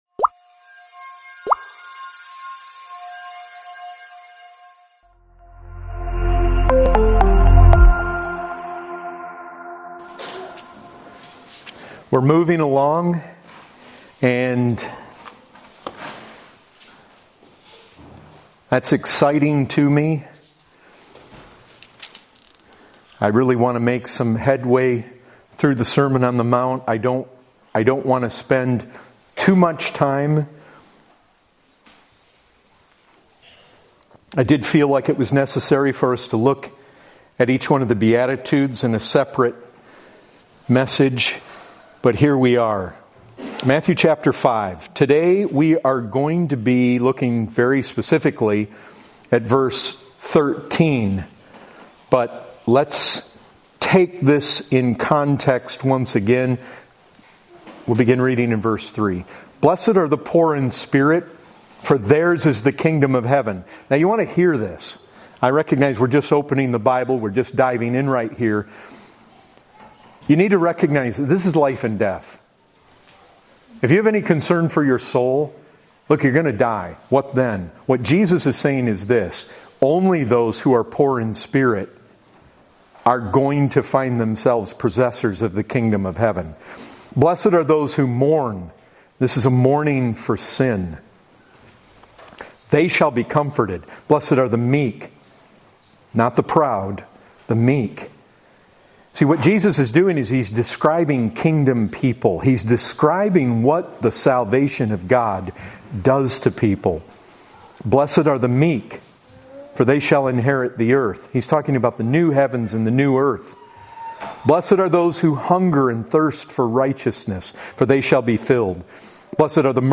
0:11 – Start of the sermon.